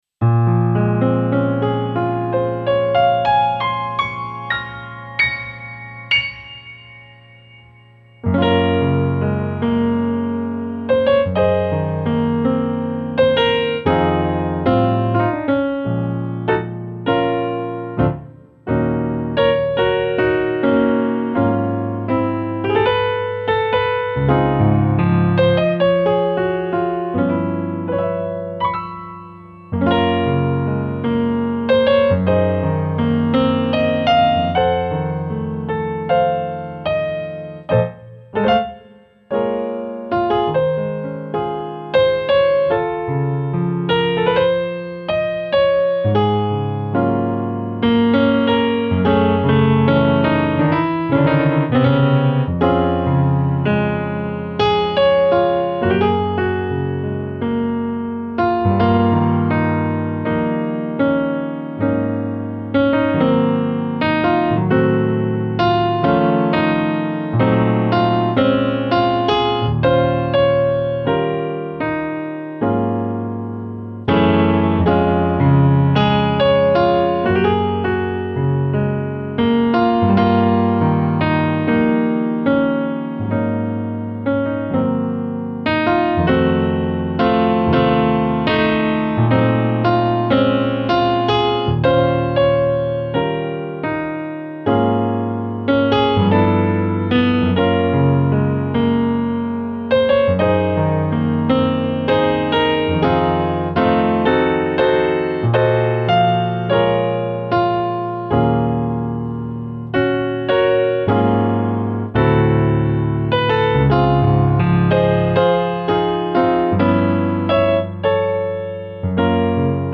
solo piano ballad arrangement